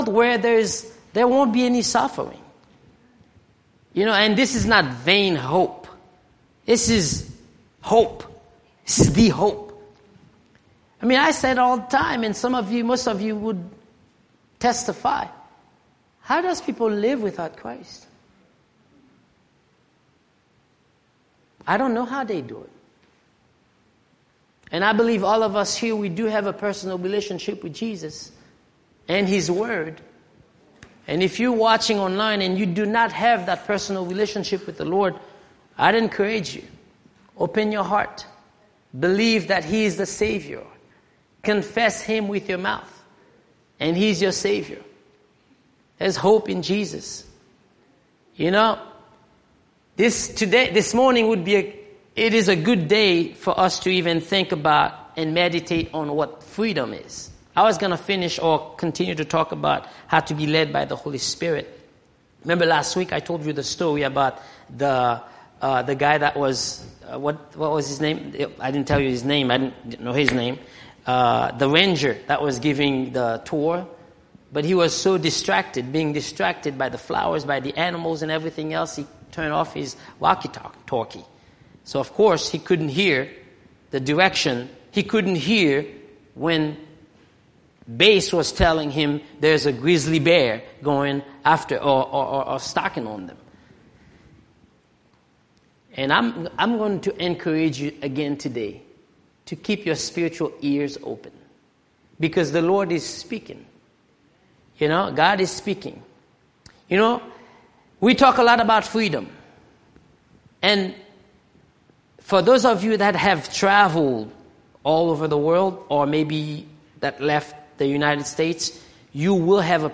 Audio recordings of sermons